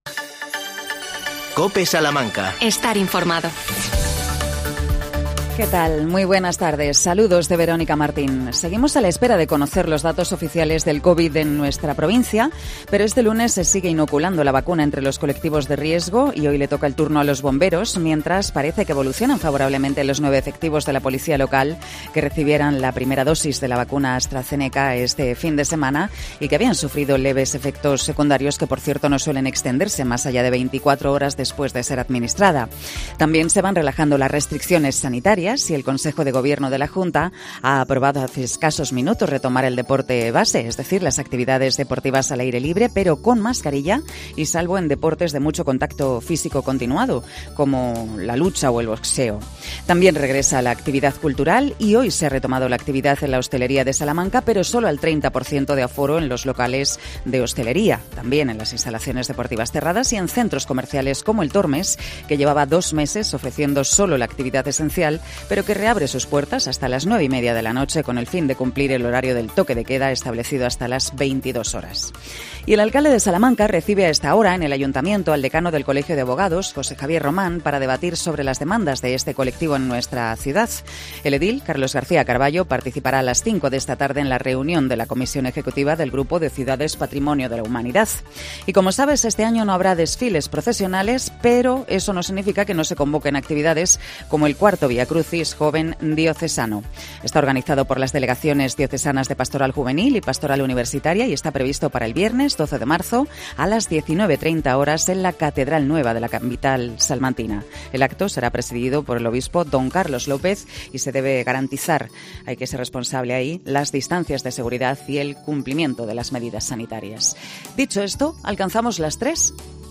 AUDIO: Una estudiante, un ama de casa y una mujer empresaria de Salamanca nos cuentan cómo ha cambiado su vida la pandemia.